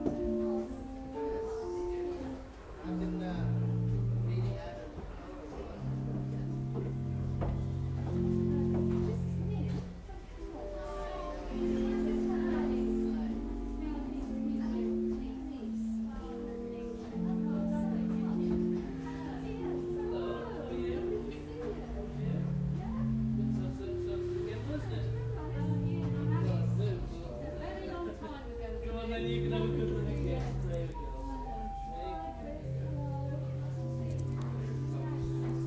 Organ Music